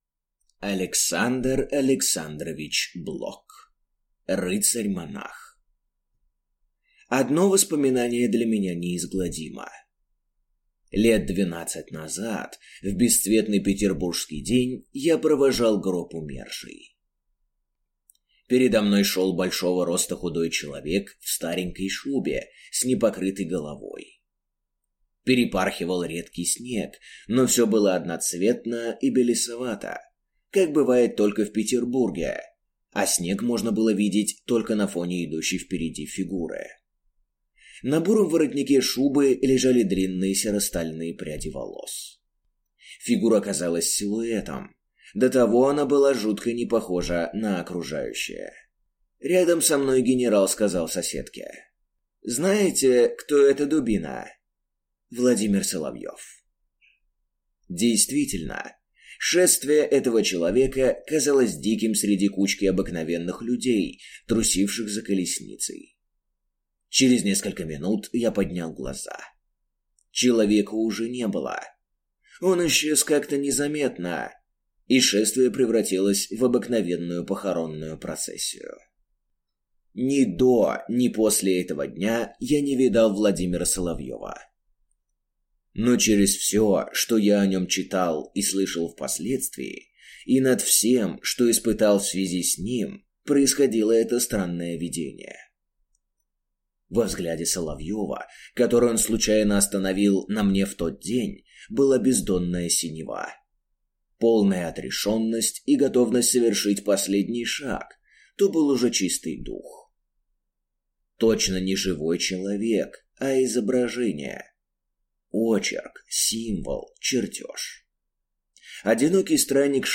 Аудиокнига Рыцарь-монах | Библиотека аудиокниг